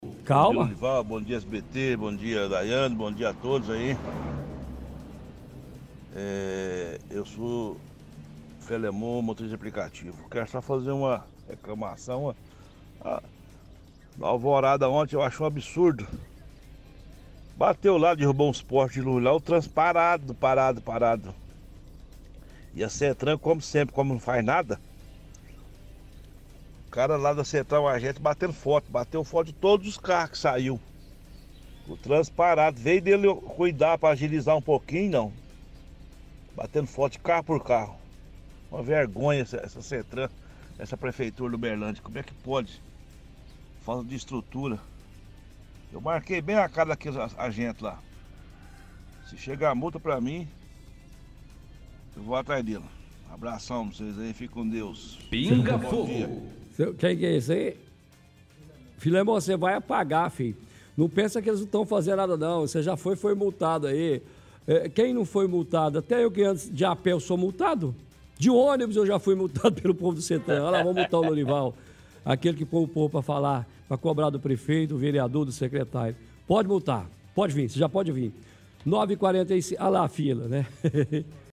– Ouvinte reclama de agentes da SETTRAN.